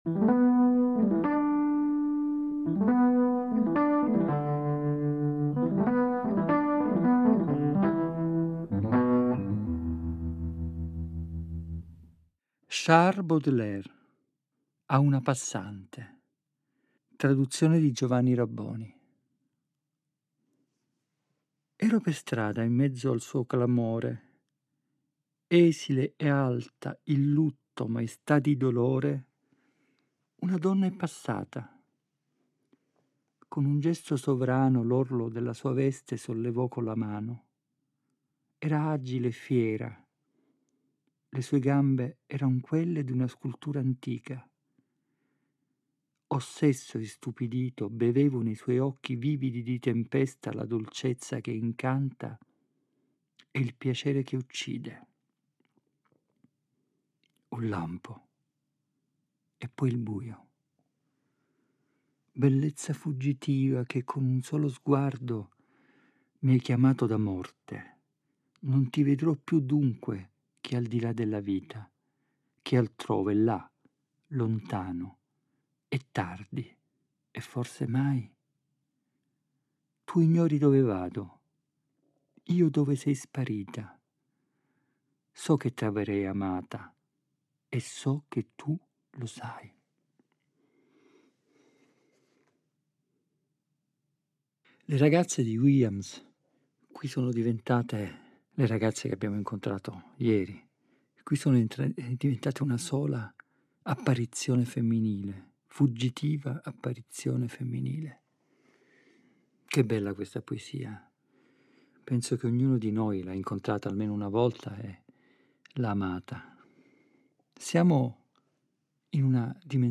Ed è a partire da questo simbolo «delle equazioni casalinghe» che hanno caratterizzato il tempo del lockdown (una parte delle registrazioni è stata pensata e realizzata proprio fra le mura domestiche) che egli ci guida nella rigogliosa selva della parola poetica per «dare aria ai pensieri».